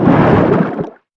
Sfx_creature_hiddencroc_swim_fast_05.ogg